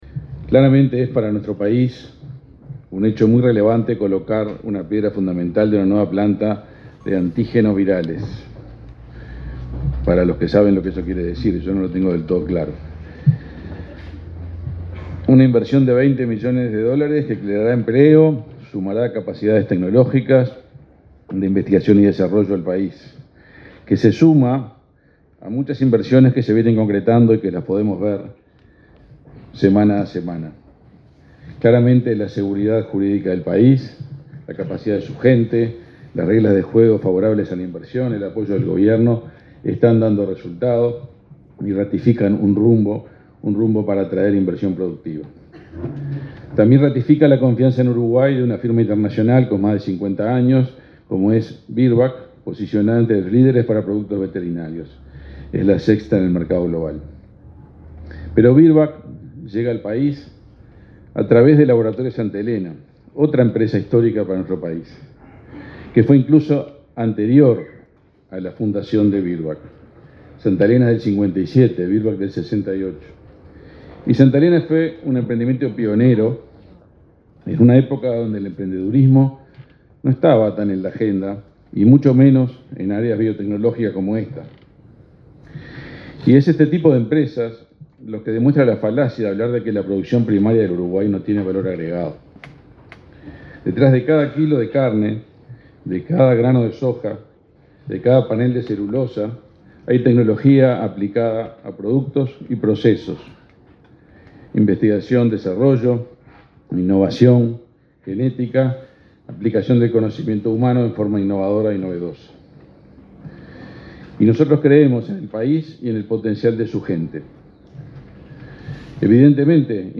Palabras del ministro de Industria, Omar Paganini
Este martes 17 en Montevideo, el ministro de Industria, Omar Paganini, participó en el acto de colocación de la piedra fundamental de una nueva planta